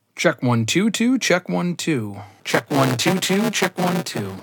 I use a Blue Yeti Mic to record and always have.
It’ll sound fine one time, and distorted the next as if there’s a buzzing while I’m speaking.
I’ve attached an audio example below of what it sounds like when it’s fine, and what it sounds like when it’s distorted.